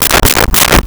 Knocking On Door With Knuckles 3 Times
Knocking on Door with Knuckles 3 times.wav